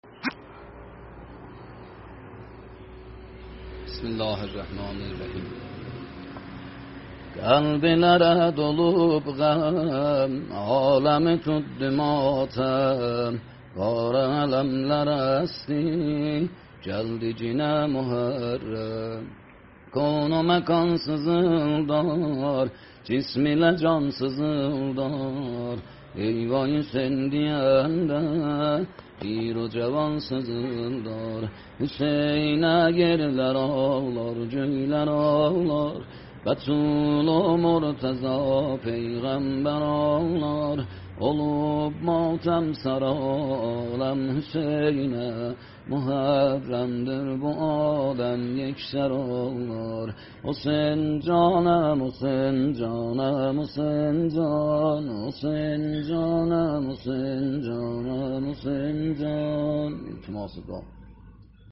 نوحه شاه حسین گویان (شاخسی)